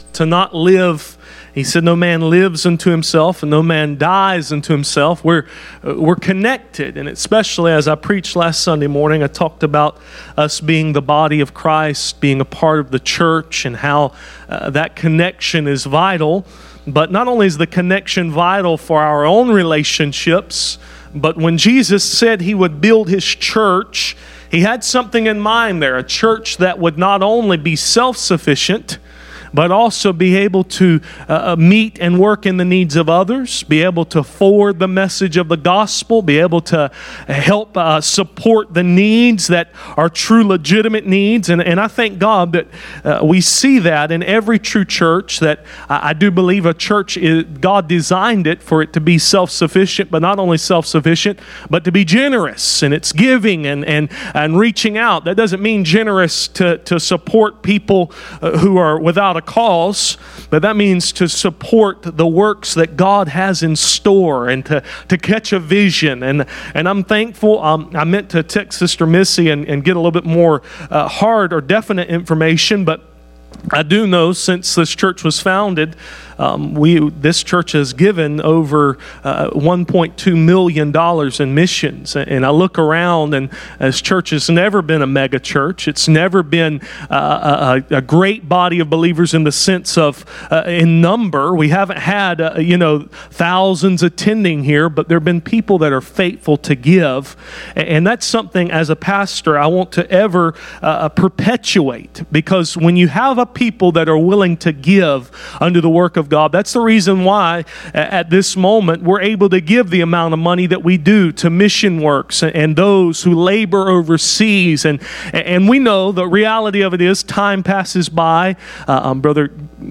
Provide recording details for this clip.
None Passage: Matthew 12:38-44 Service Type: Sunday Morning « The Battlefront of Impatience